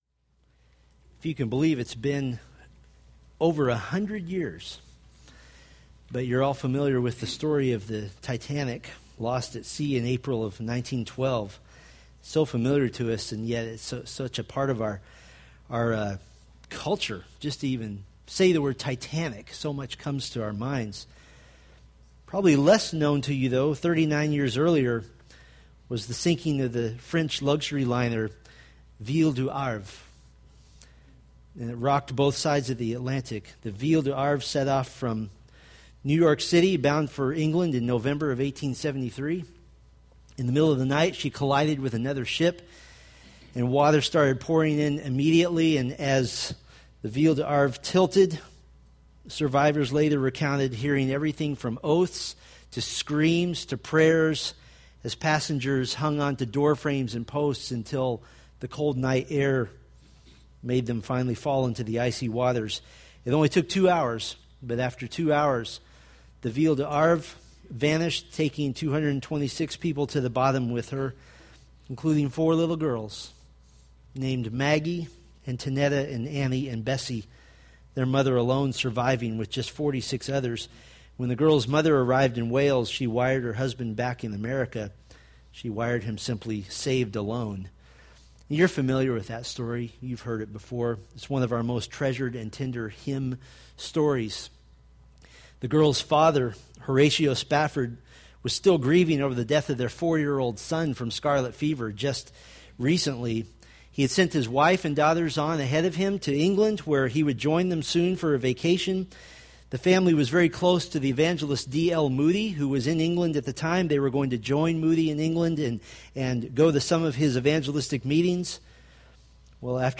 2 Thessalonians Sermon Series